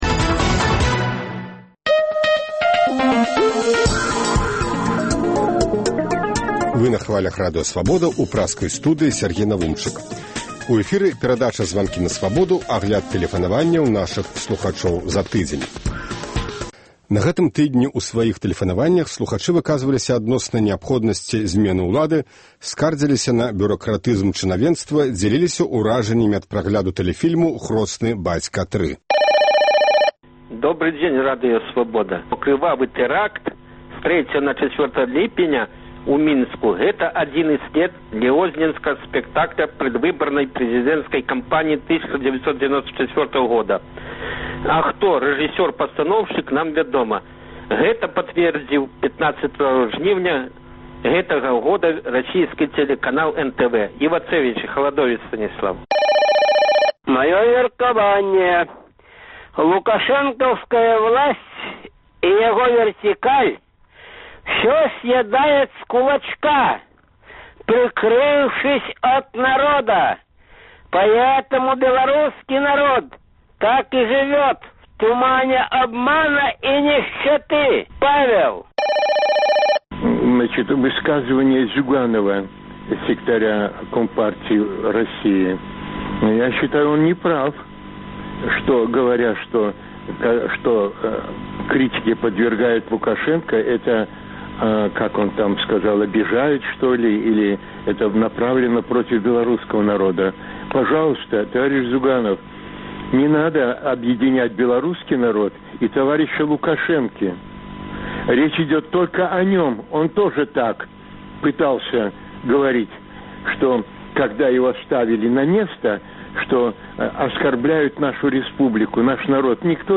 Агляд тэлефанаваньняў за тыдзень